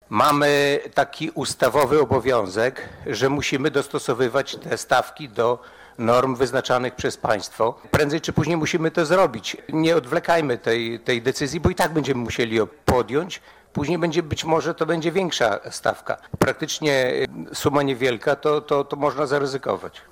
Jacek Piorunek, członek zarządu woj. podlaskiego wyjaśniał, że podwyżki wynikają z ustawy: